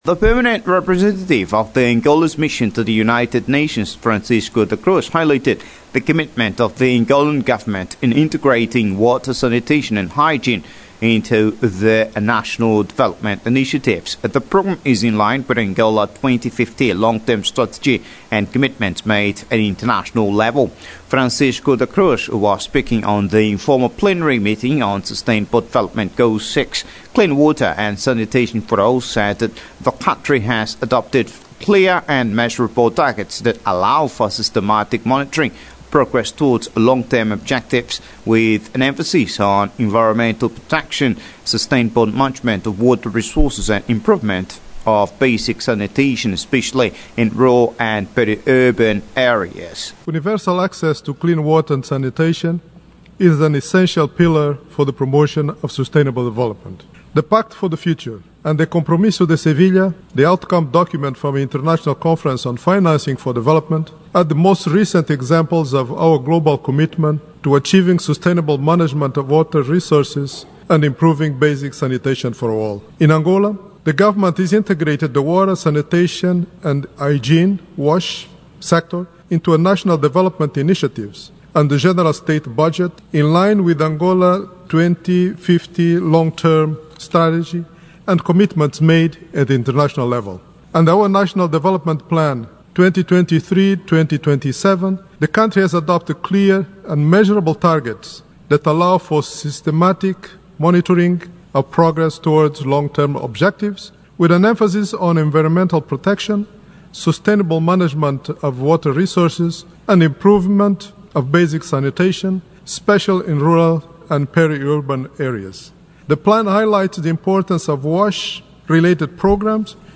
Francisco da Cruz, who was speaking on the informal plenary meeting on Sustainable Development Goal 6: clean water and sanitation for all, said that the country has adopted clear and measurable targets that allow for systematic monitoring of progress towards long-term objectives, with an emphasis on environmental protection, sustainable management of water resources and improvement of basic sanitation, especially in rural and peri-urban areas.